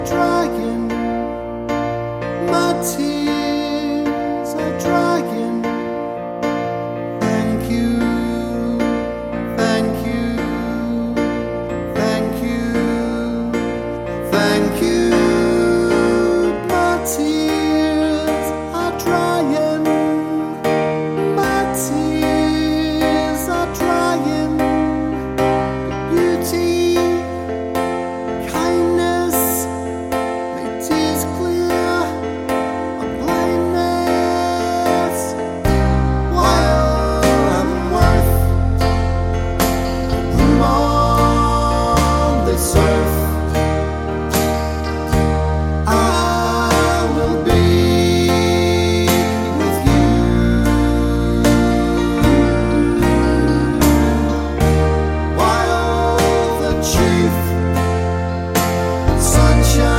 for solo male Pop (1980s) 4:35 Buy £1.50